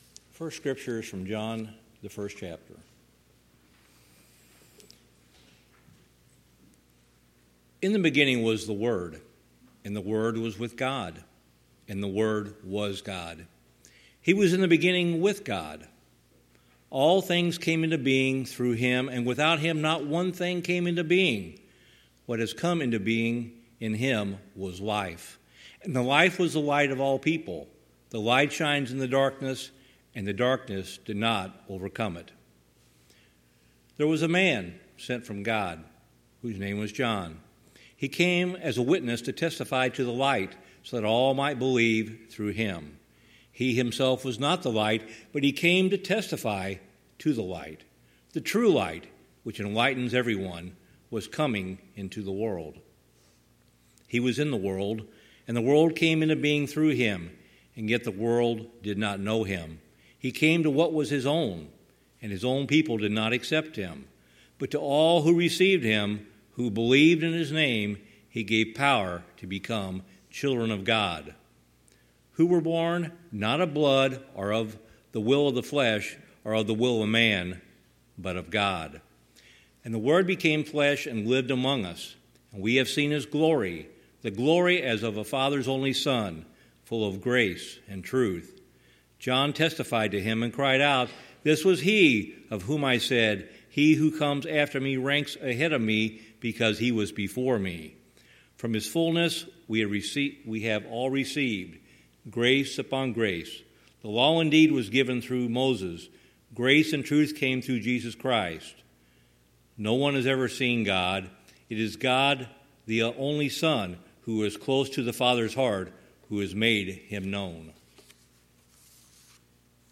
Matthew 2:1-12 Service Type: Sunday Morning « Hope Is Here!